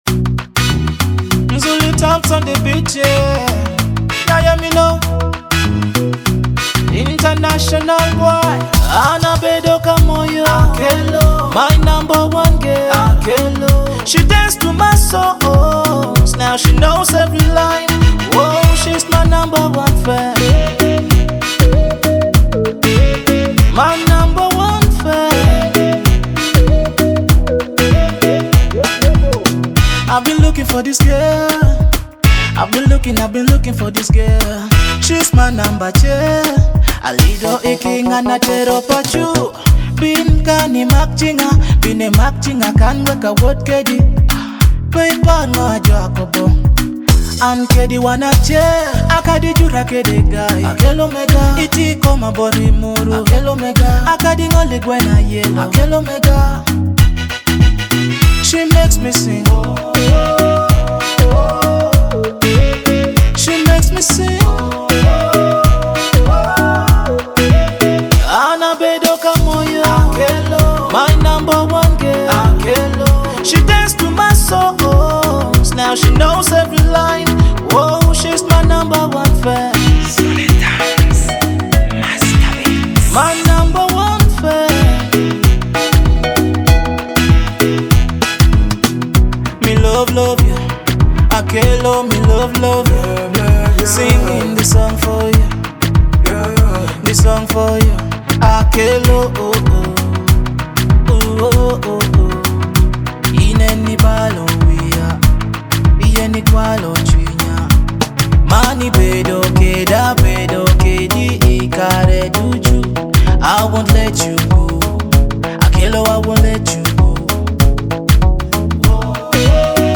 blends traditional rhythms with modern African beats